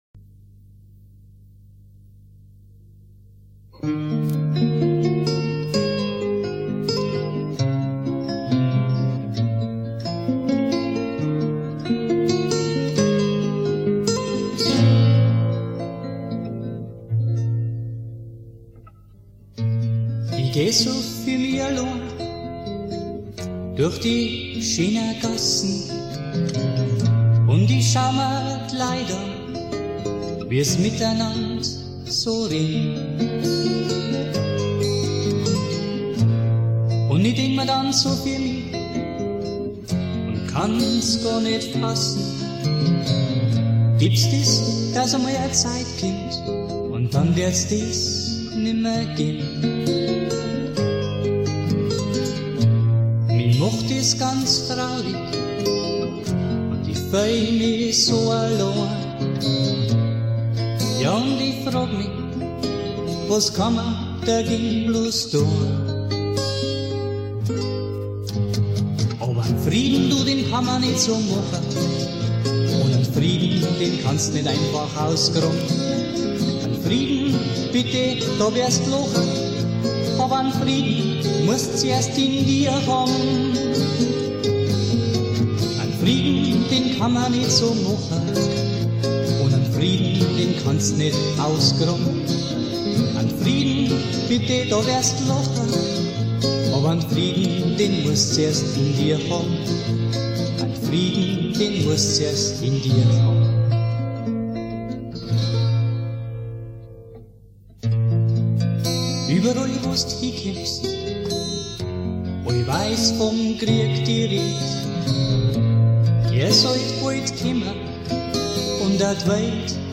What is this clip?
Gesang, Gitarre